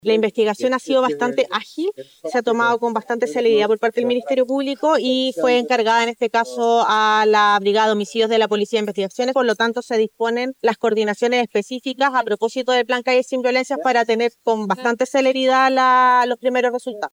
Por su parte, la seremi de Seguridad, Alejandra Romero, detalló que se han aumentado los trabajos con Carabineros bajo el plan Calles Sin Violencias, más en específico, de los patrullajes liderados por la policía para aportar en la investigación.